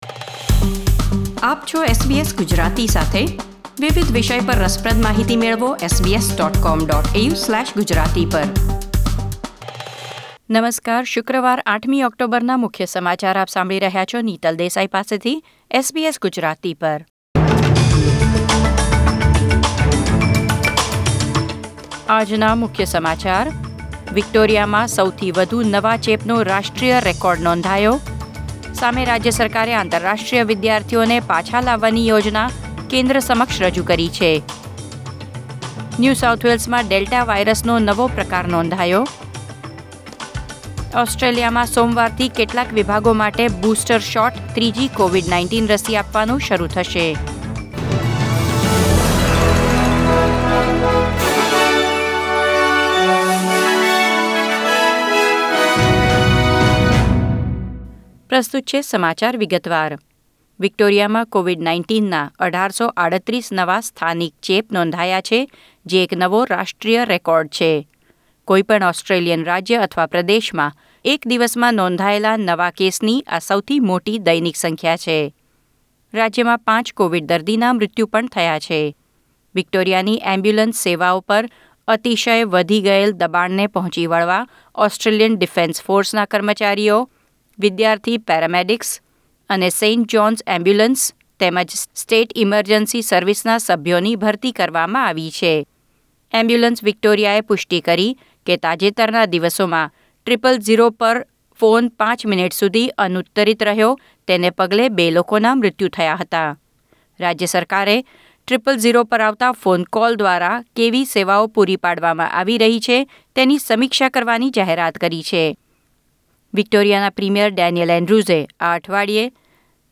SBS Gujarati News Bulletin 8 October 2021